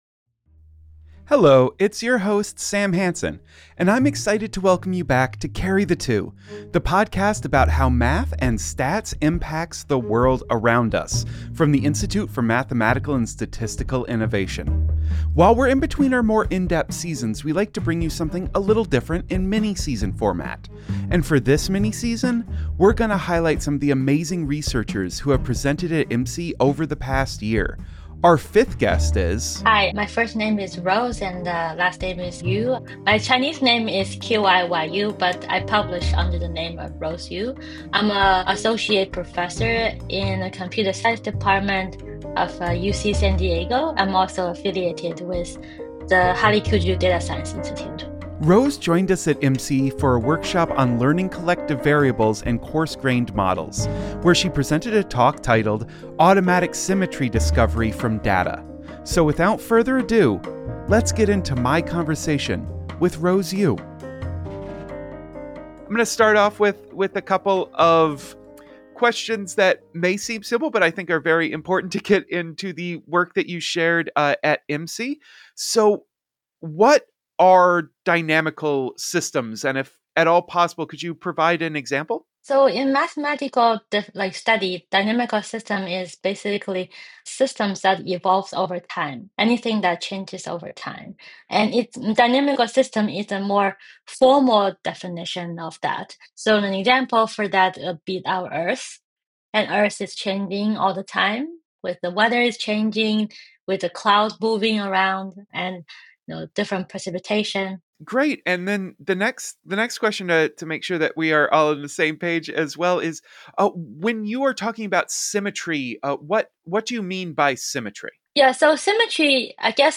Welcome to Carry the Two, the podcast about how math and statistics impact the world around us from the Institute for Mathematical and Statistical Innovation.